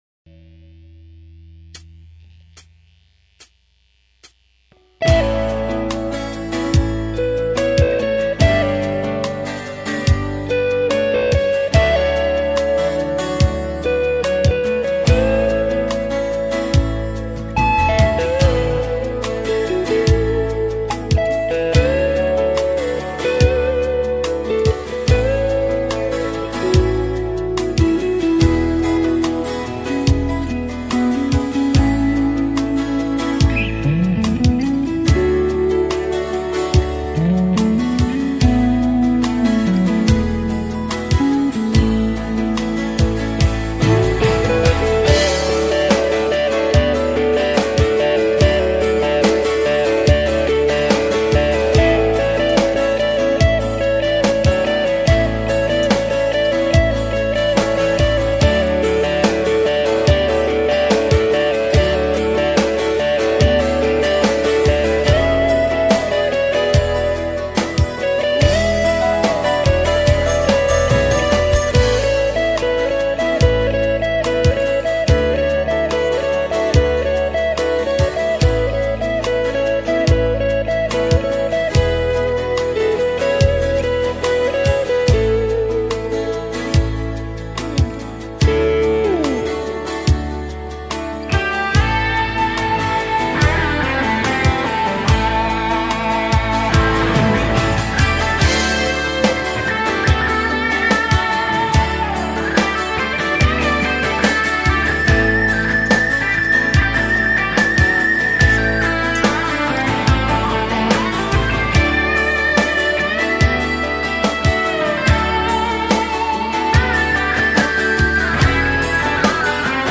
das ist ja eine Gitarrenwand, die da perfekt zusammen hinter einem steht
ich habe dann einfach ne Schüppe Gain draufgelegt und wie gesagt ein paar wenige Noten drüber verteilt.